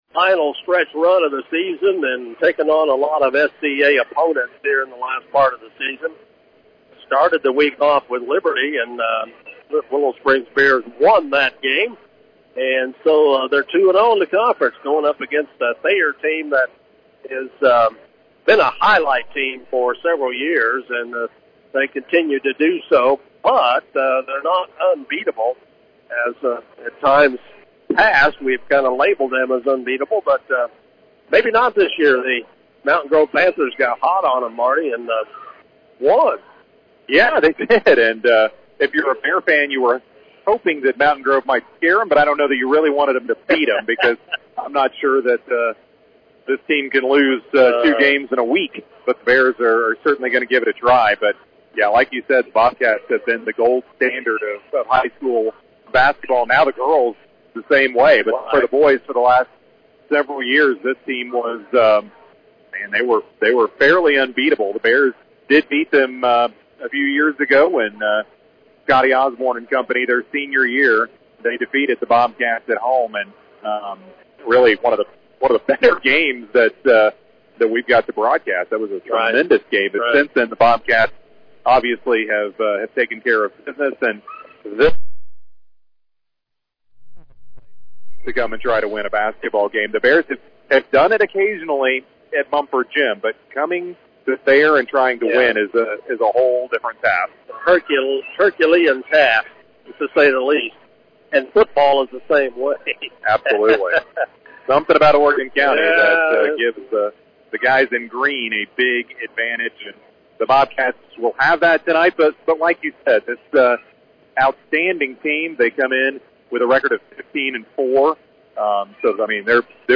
Game Audio Below: Quarter 1: The Bears start off hot with a solid lead over the Bobcats.